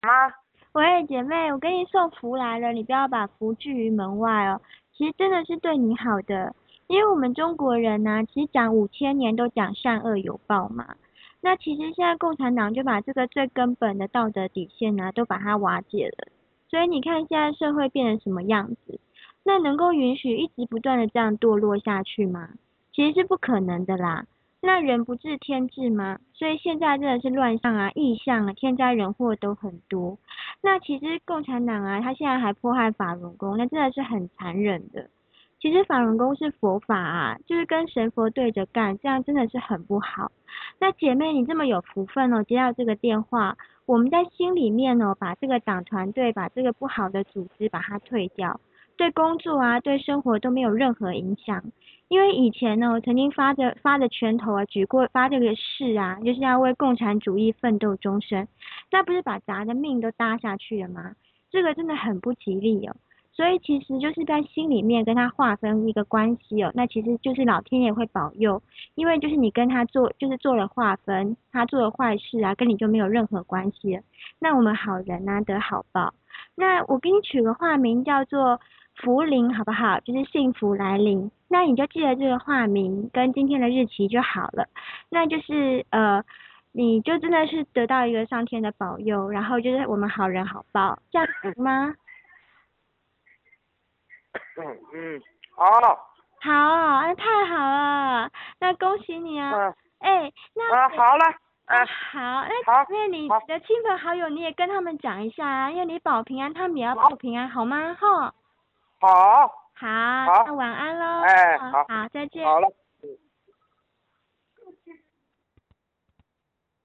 【示範錄音】老翁勸退語音檔